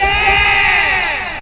VOX SHORTS-1 0008.wav